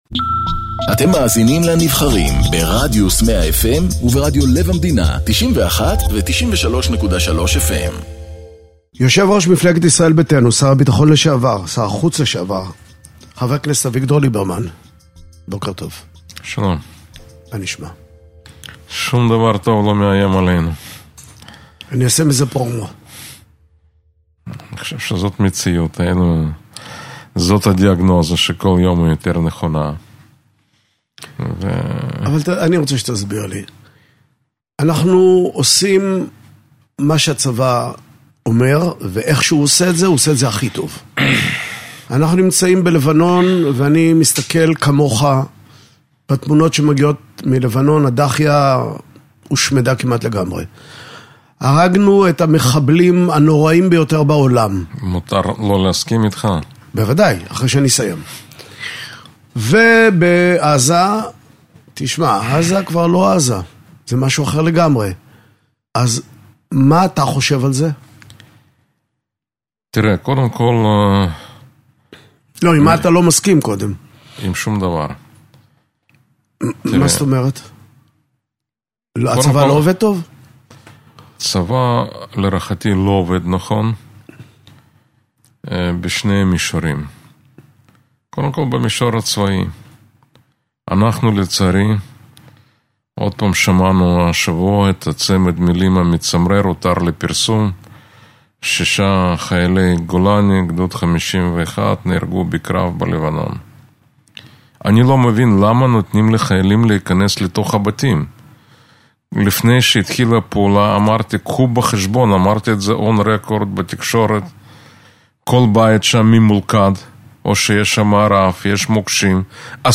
מראיין את יו"ר "ישראל ביתנו", שר הביטחון לשעבר , חבר הכנסת אביגדור ליברמן